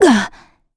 Valance-Vox_Damage_kr_02.wav